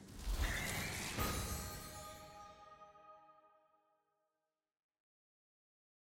sfx-pm-level-all-completed.ogg